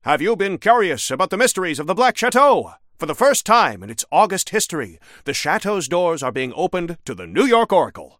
Newscaster_headline_56.mp3